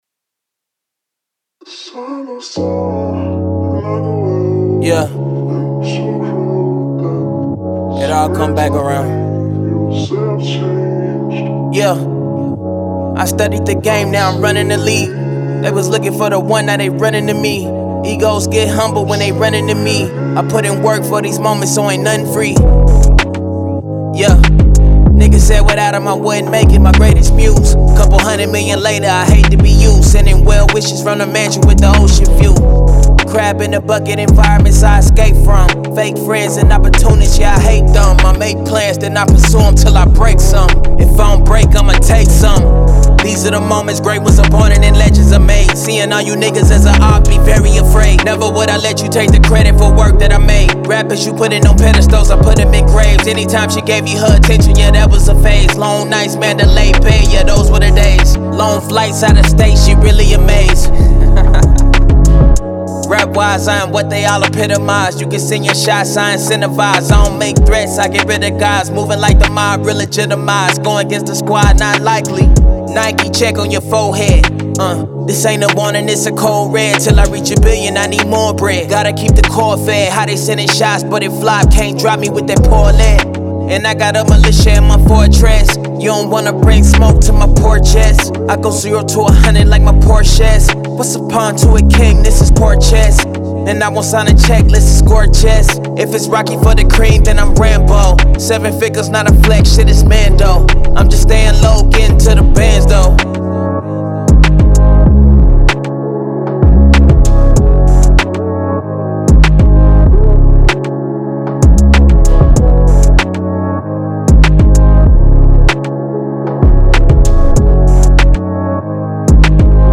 Hip Hop
C min